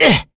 Mitra-Vox_Damage_01.wav